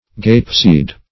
Search Result for " gapeseed" : The Collaborative International Dictionary of English v.0.48: Gapeseed \Gape"seed`\ (g[=a]p"s[=e]d), n. 1. Any strange sight.